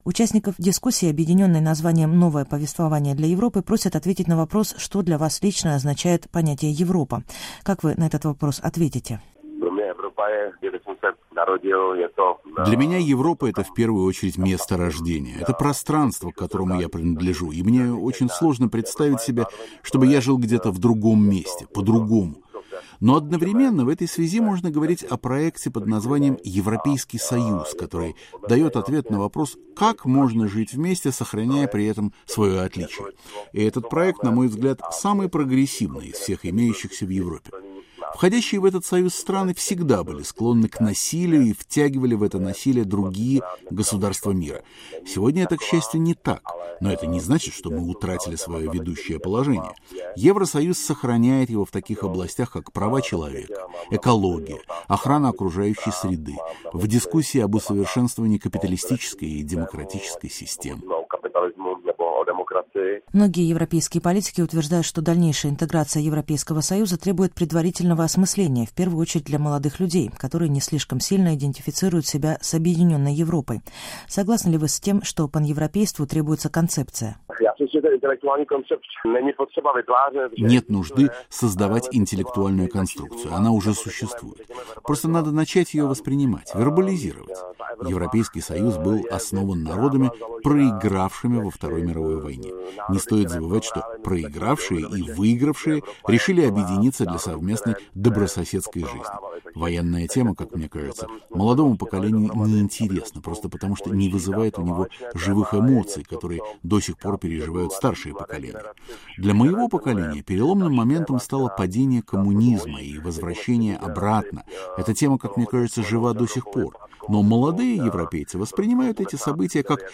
Беседа с экономистом Томашем Седлачеком